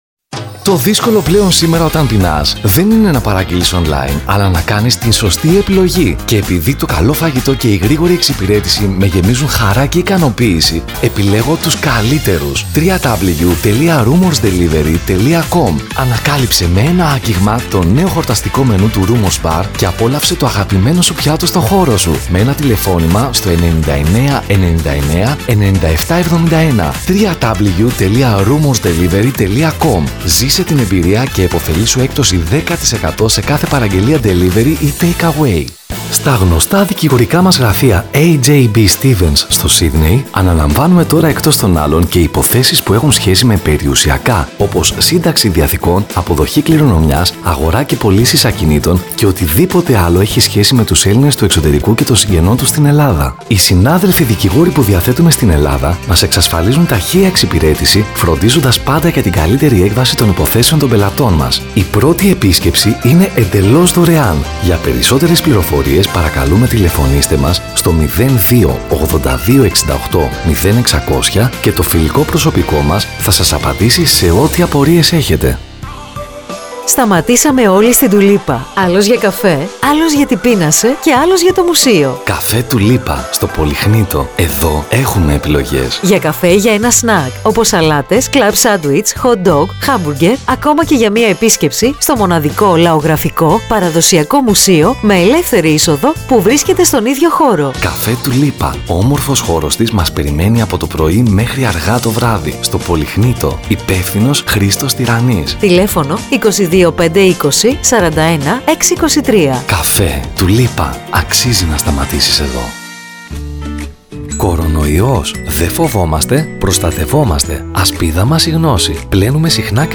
Greek male voice over
Ανδρικες Φωνες
Ραδιοφωνικά σποτ – Εκφωνητές – Ανδρικές φωνές